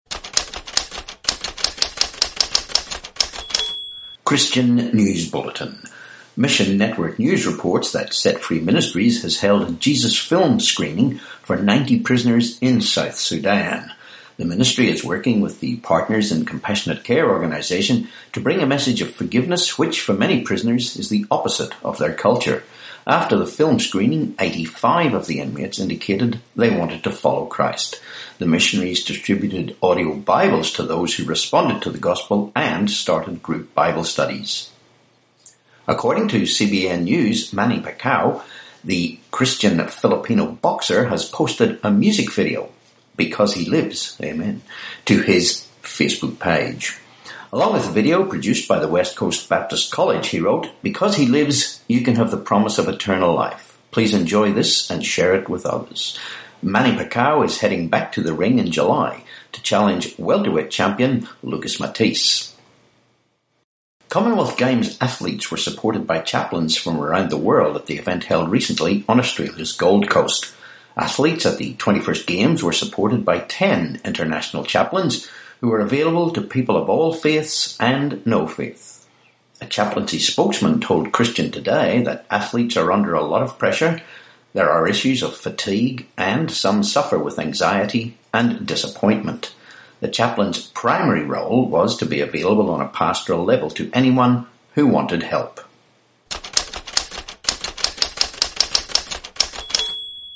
22Apr18 Christian News Bulletin